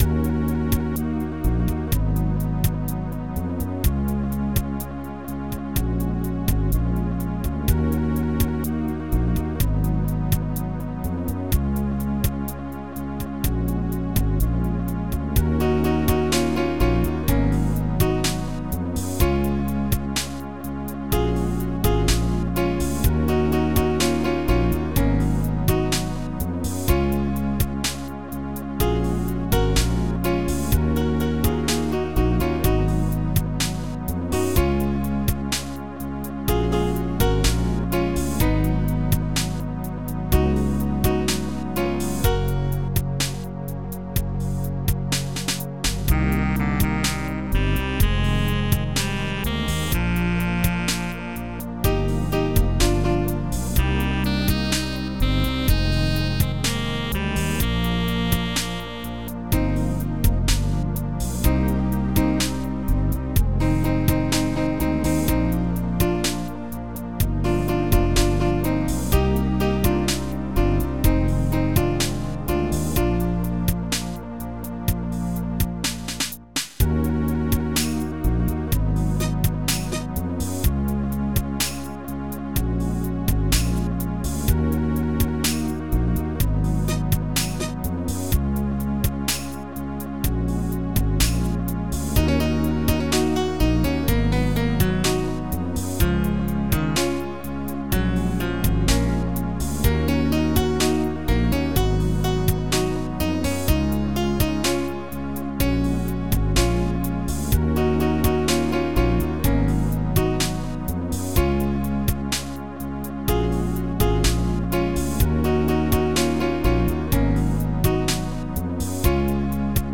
Protracker Module